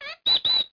parrot0a.mp3